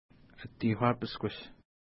Atiku-uapishkuss Next name Previous name Image Not Available ID: 202 Longitude: -61.5105 Latitude: 55.5385 Pronunciation: əti:hkwa:piskuʃ Translation: Caribou White Mountain (small) Feature: snow covered summit